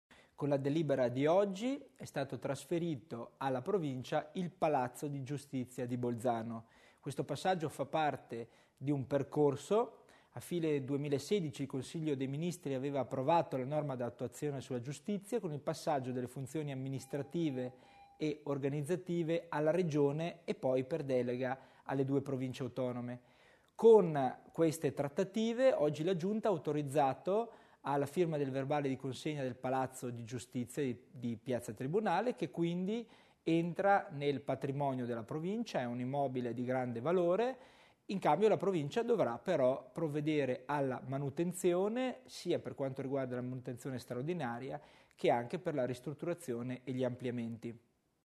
Il Vicepresidente Tommasini spiega il passaggio di proprietà degli uffici giudiziari di Bolzano